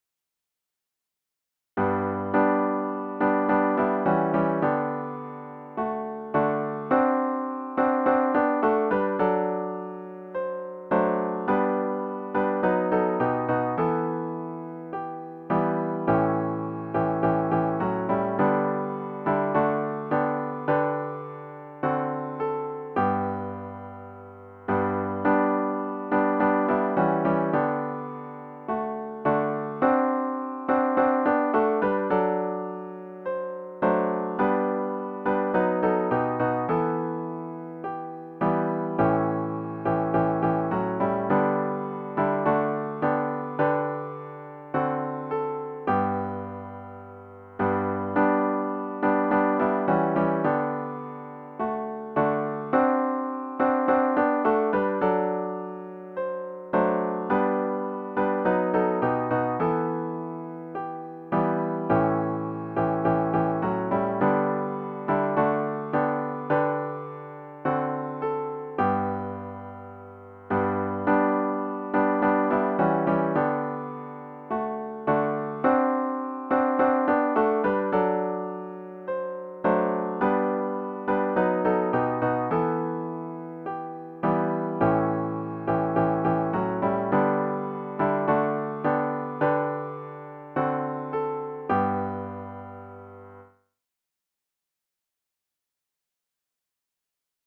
CLOSING HYMN “O Love That Wilt Not Let Me Go” GtG 833
zz-833-O-Love-That-Wilt-Not-Let-Me-Go-4vs-piano-only.mp3